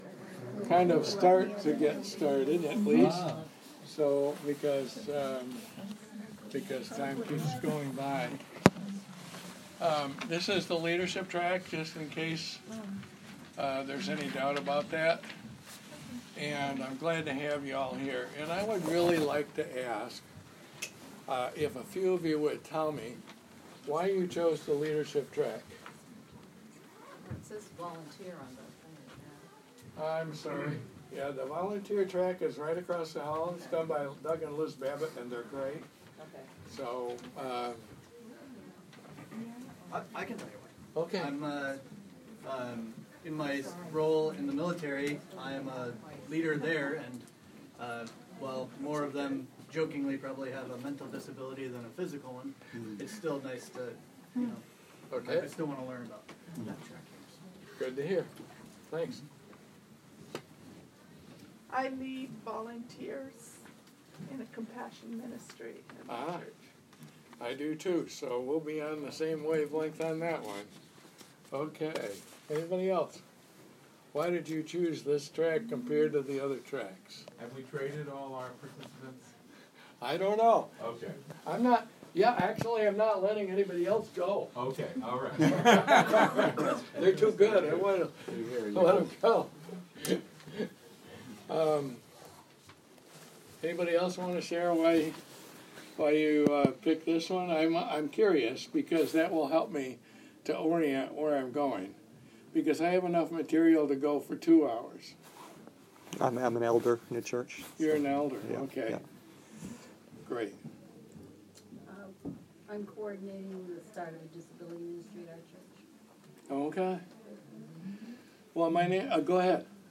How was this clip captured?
Disability and the Gospel Conference